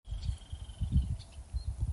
Sanã-parda (Laterallus melanophaius)
Nome em Inglês: Rufous-sided Crake
Condição: Selvagem
Certeza: Gravado Vocal